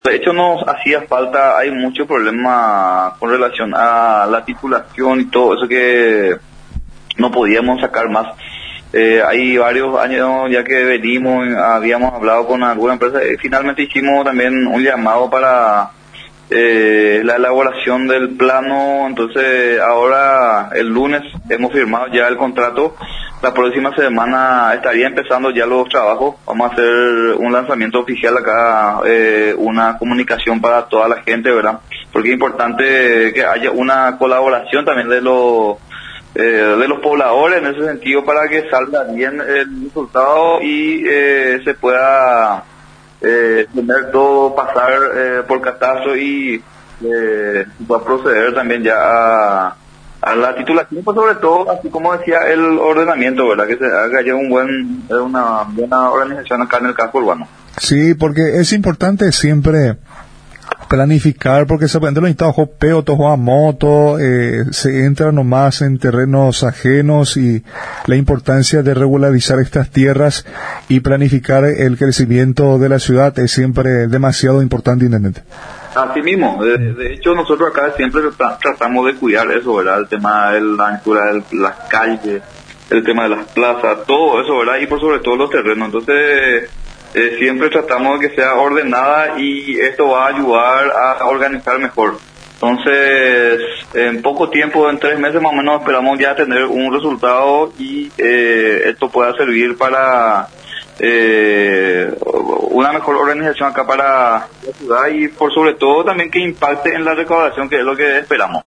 EDITADO-6-ABG.-JUAN-MANUEL-AVALOS-INTENDENTE.mp3